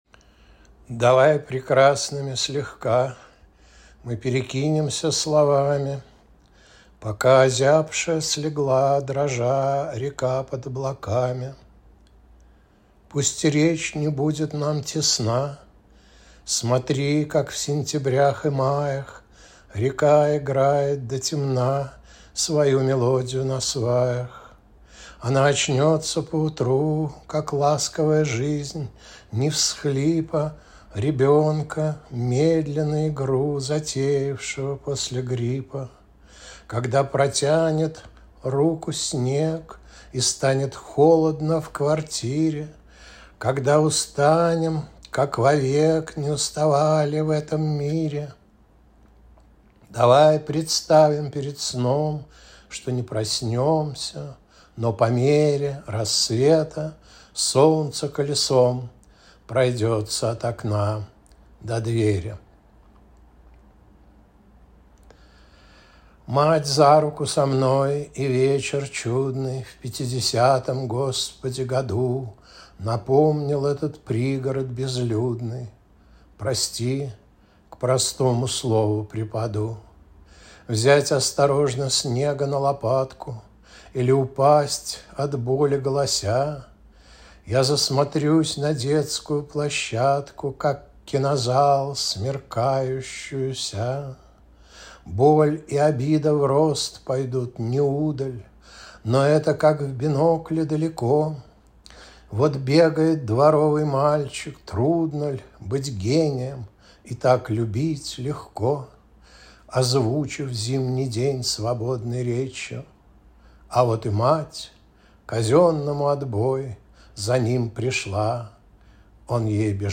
chitayu-svoi-stihi.mp3